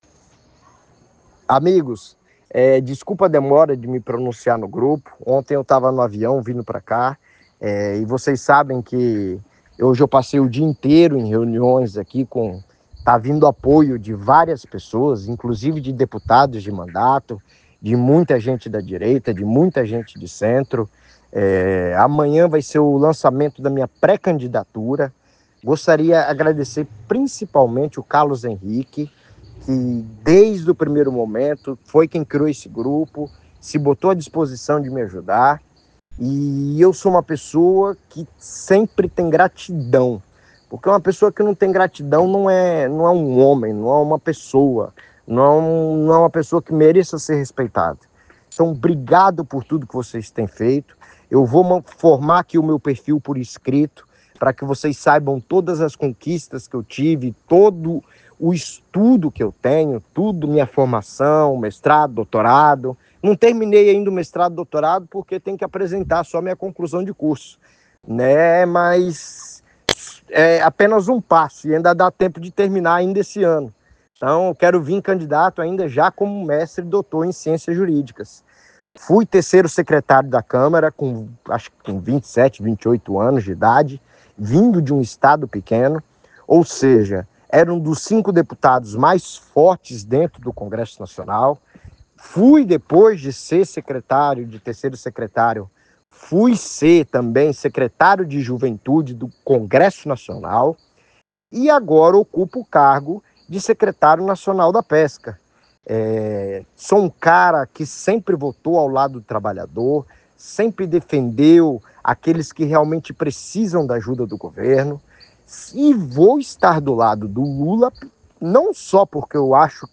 Ouça o áudio de Expedito Netto direcionado ao grupo de Whatsapp “Expedito Netto governador”, onde ele expõe as principais razões para se candidatar ao governo de Rondônia pelo PT.